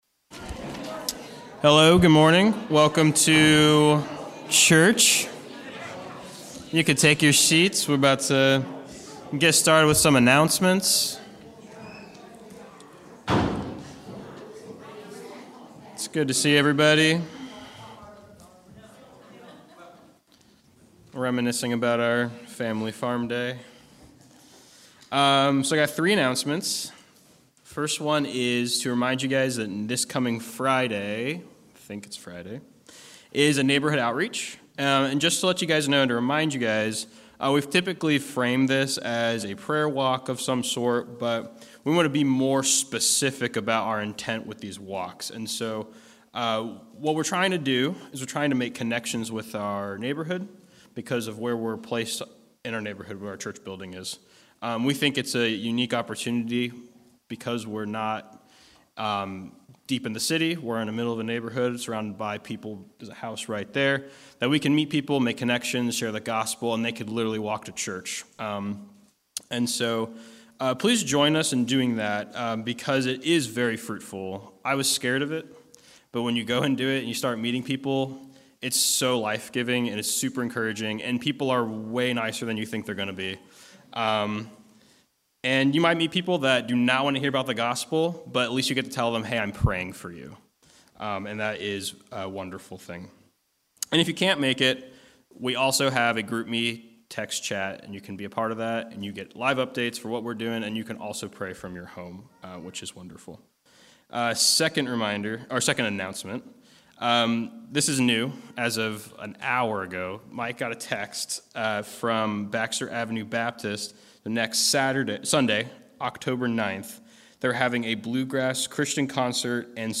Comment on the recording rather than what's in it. October 02 Worship Audio – Full Service